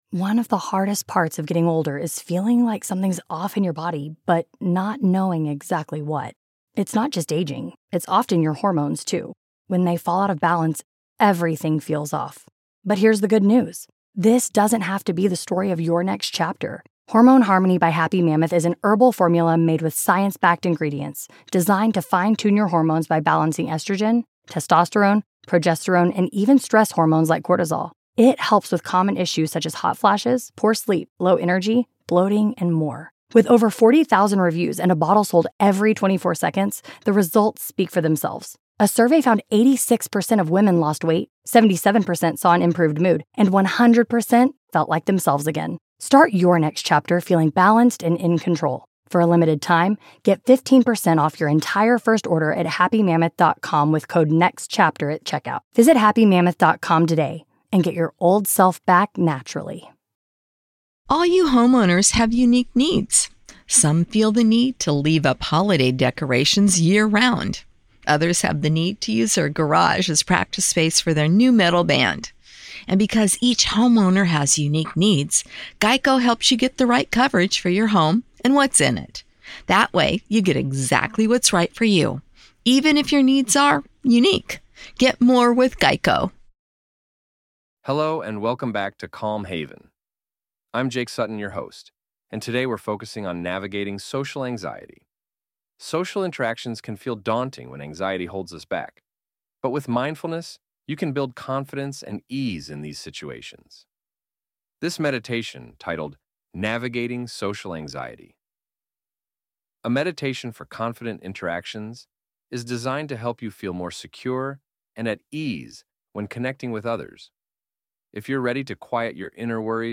This meditation, titled Navigating Social Anxiety: A Meditation for Confident Interactions, is designed to help you feel more secure and at ease when connecting with others. If you’re ready to quiet your inner worries and step into social confidence, find a comfortable position, take a deep breath, and let’s begin.